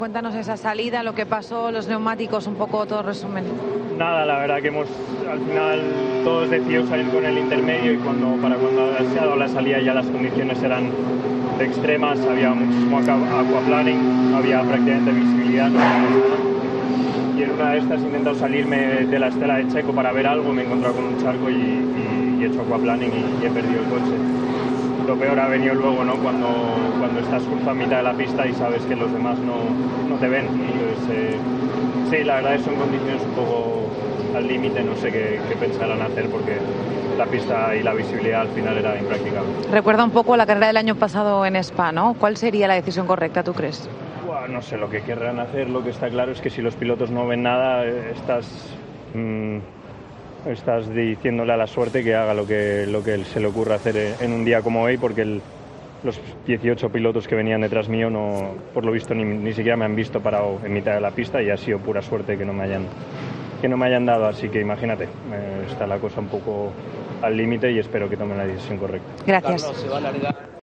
"Está claro que si los pilotos no ven nada, estás diciéndole a la suerte que haga lo que se le ocurra, en un día como hoy; porque los 18 pilotos que iban detrás mía ni siquiera me han visto", declaró este domingo, en Suzuka, al canal de televisión Dazn, el español de Ferrari.